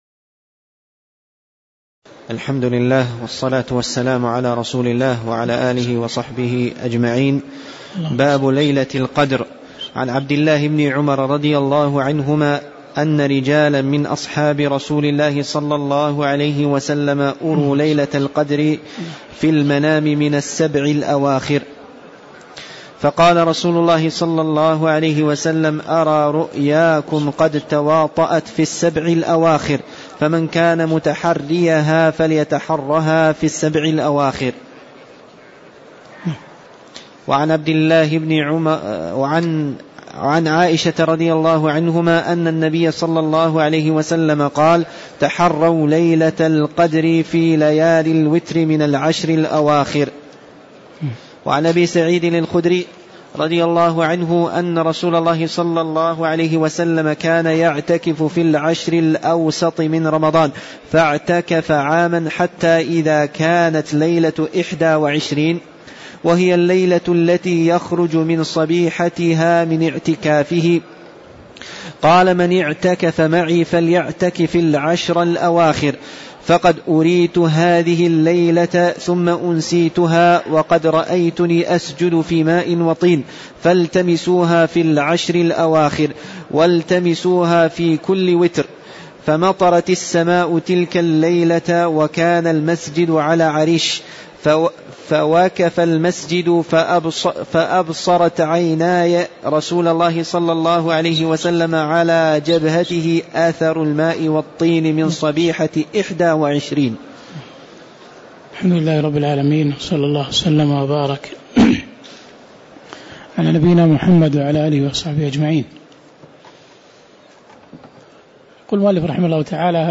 تاريخ النشر ٤ شعبان ١٤٣٧ هـ المكان: المسجد النبوي الشيخ